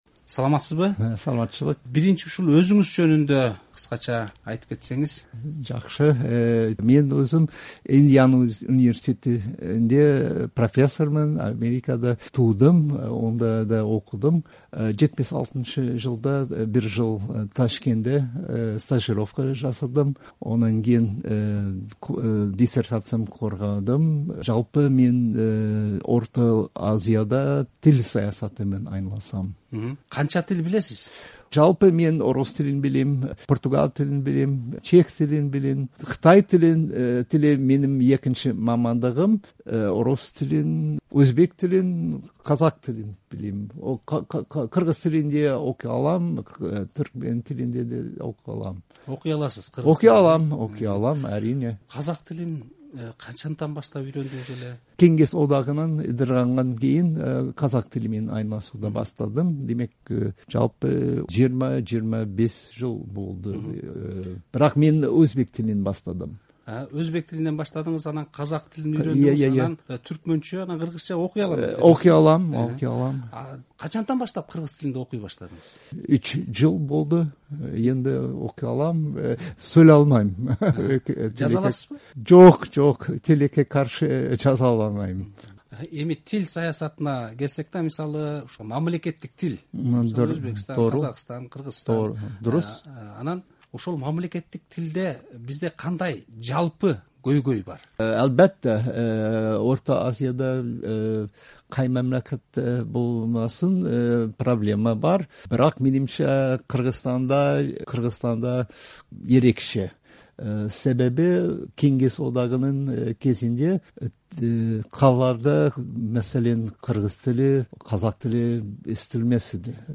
“Азаттык” радиосунун студиясына келип, Борбор Азиядагы мамлекеттик тил саясаты боюнча маек курду.